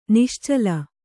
♪ niścala